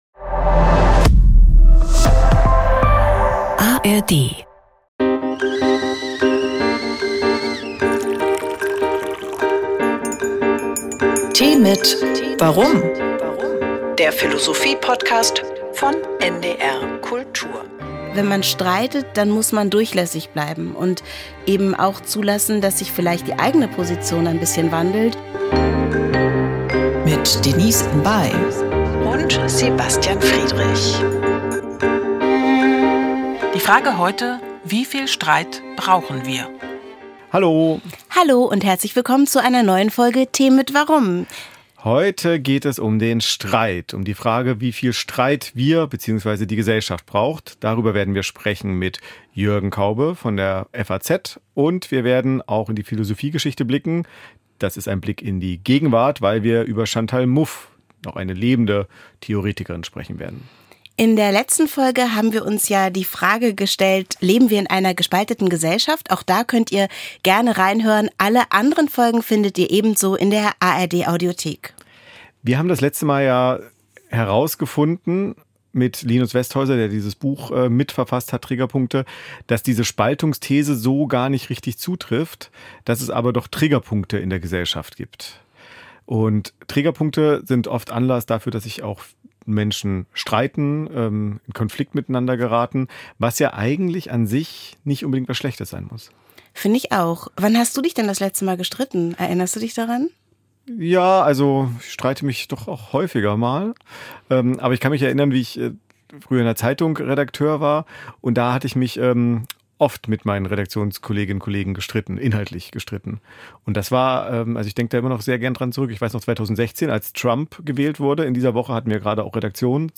Kinderhörspiel: Der Sängerkrieg der Heidehasen - 01.04.2024